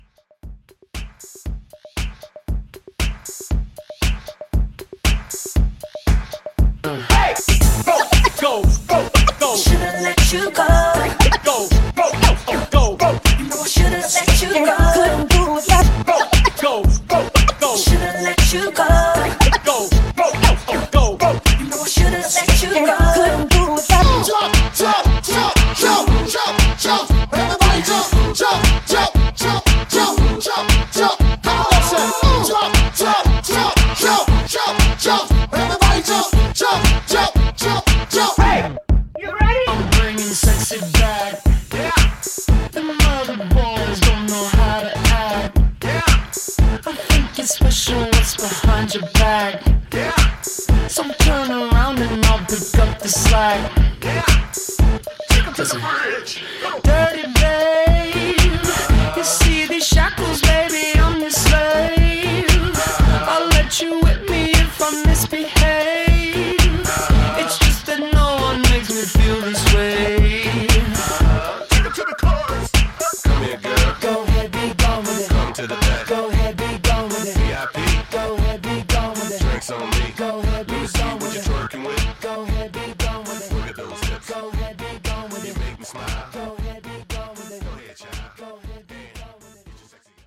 Genre: MEGAMIXES
Clean BPM: 98 - 103 Time